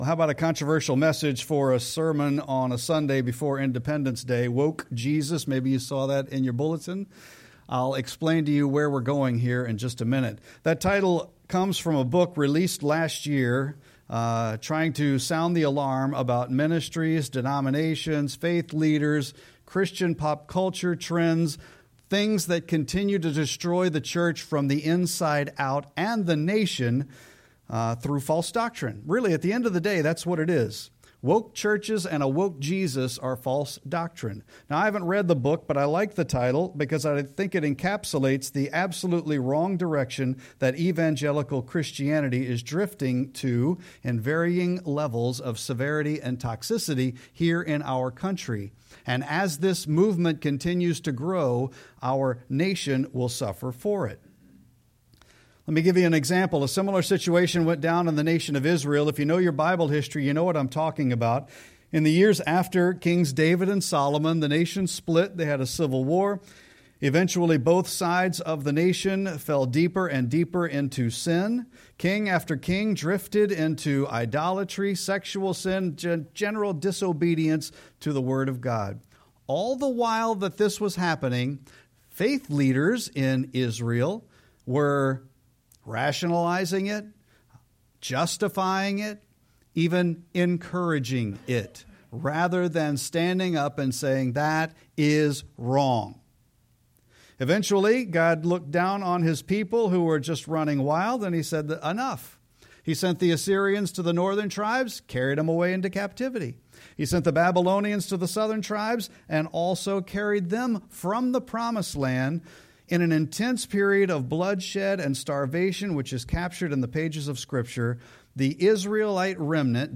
Sermon-6-29-25.mp3